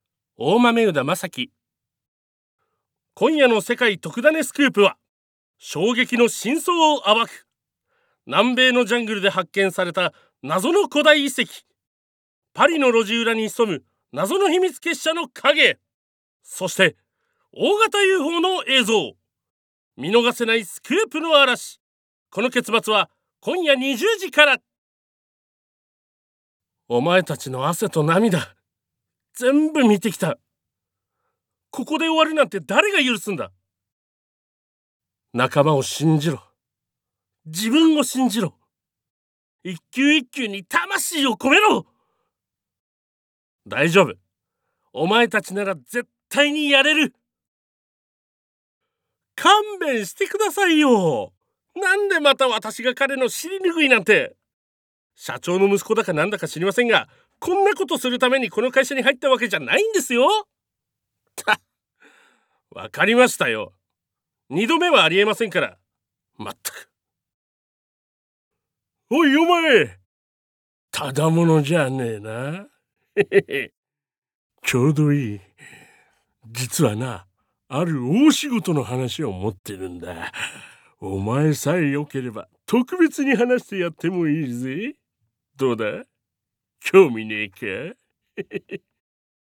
One shot Voice（サンプルボイスの視聴）